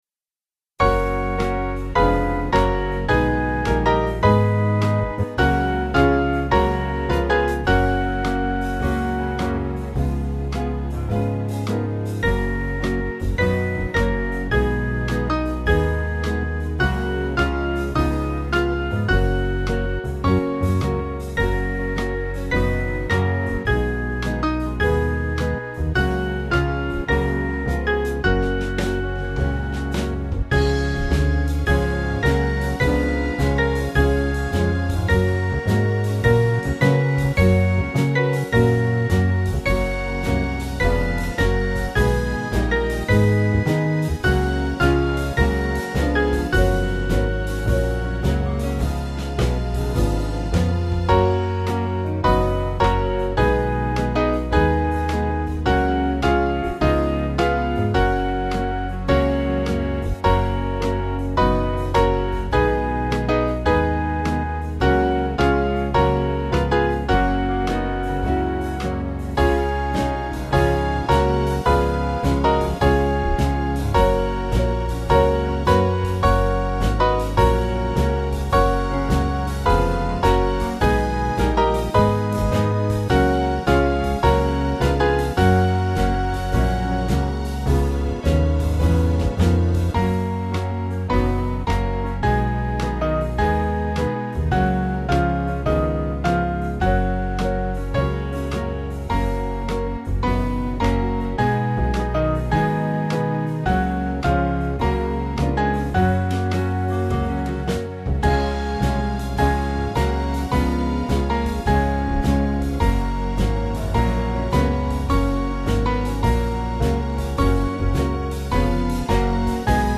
Swing Band